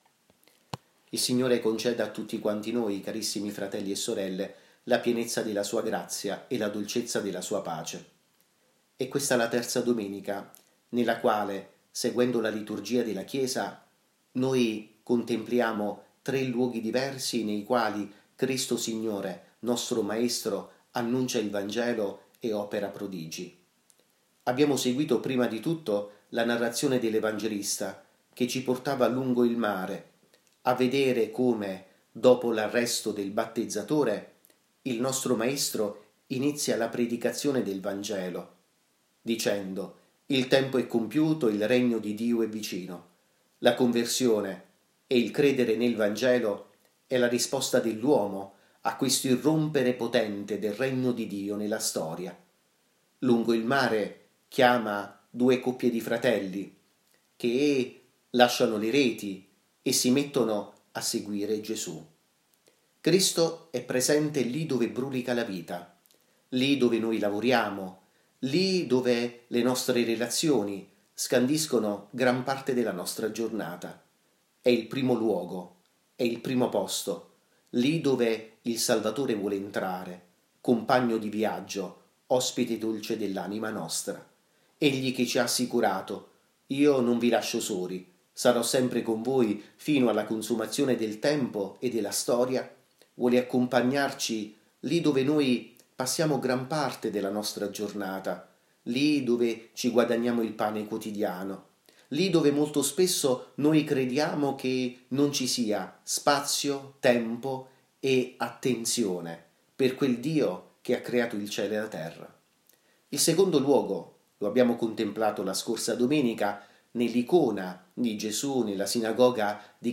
Omelia audio